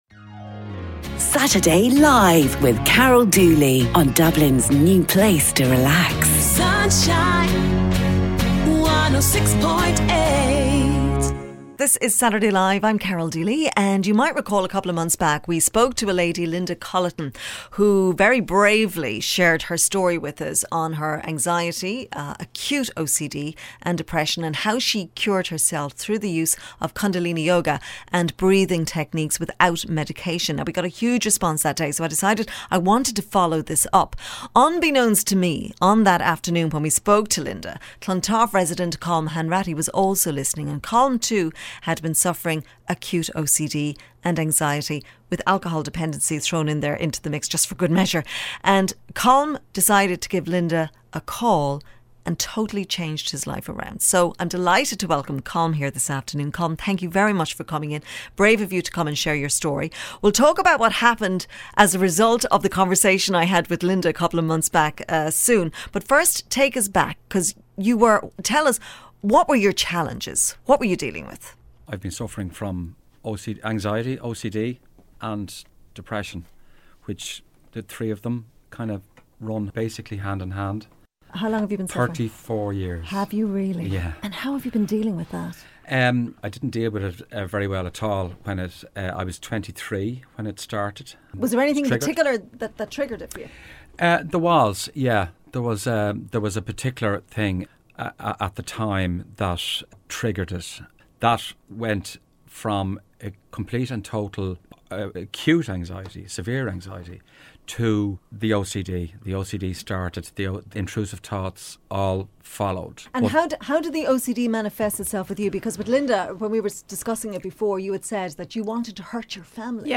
Obsessive compulsive disorder therapy interview